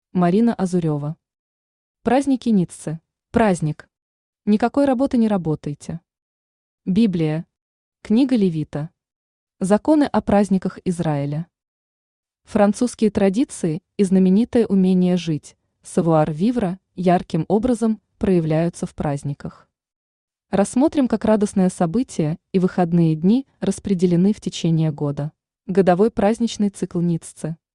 Аудиокнига Праздники Ниццы | Библиотека аудиокниг
Aудиокнига Праздники Ниццы Автор Марина Азурева Читает аудиокнигу Авточтец ЛитРес.